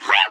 SePvGrabHeavy_InWater.wav